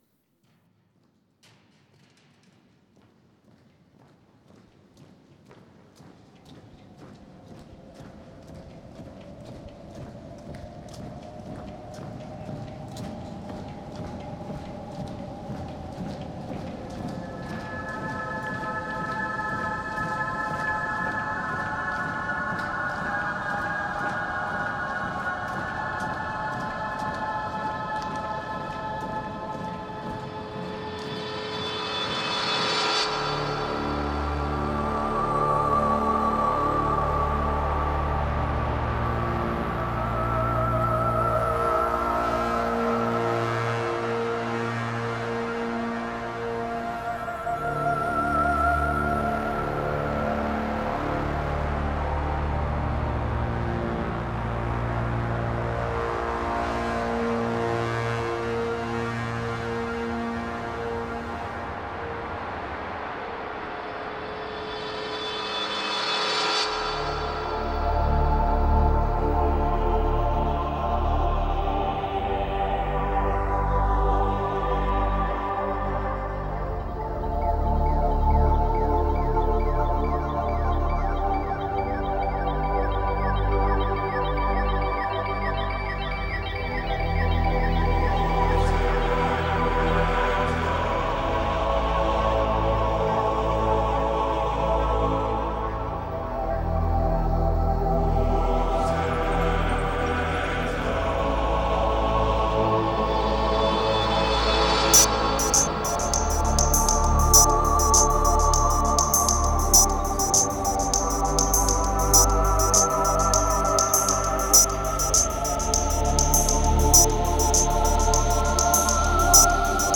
Музыка для релаксации